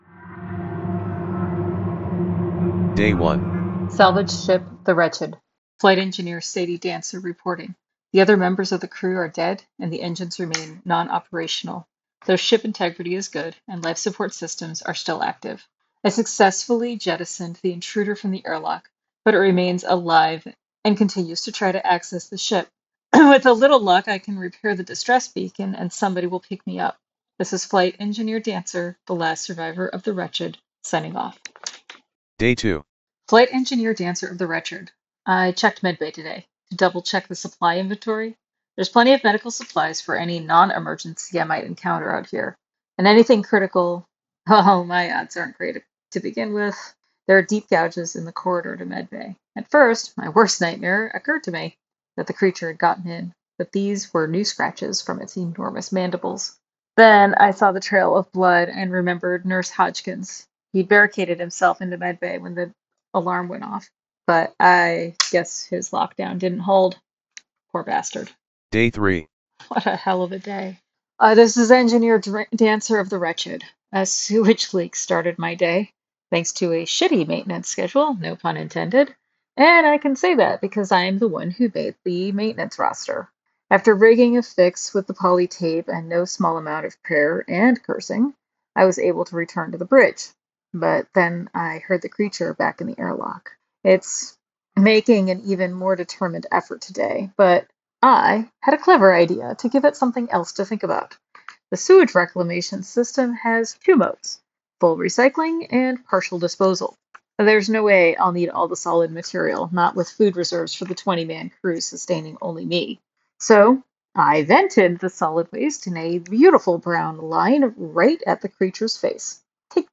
This fictional work is an “actual play” from the solo journaling game called “The Wretched” by Chris Bissette.